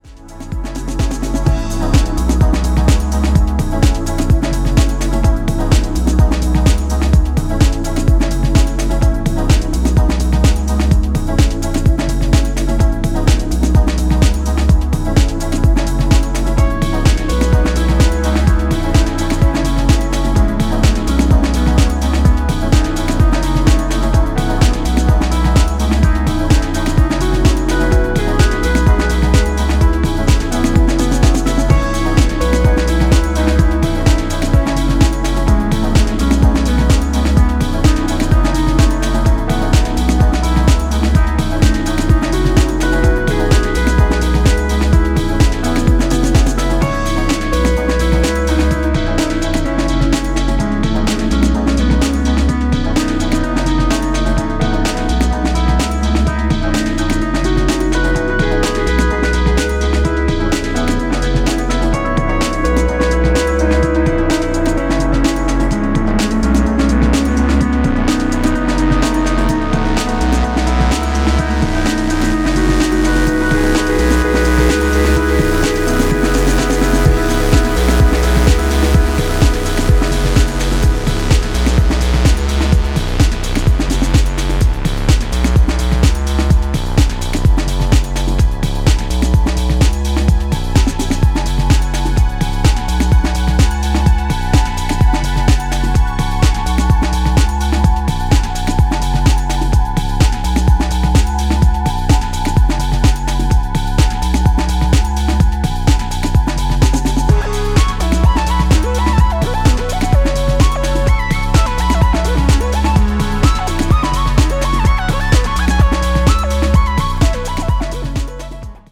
ベテランならではの滋味深いバレアリック・ハウス